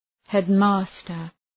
Προφορά
{,hed’mæstər}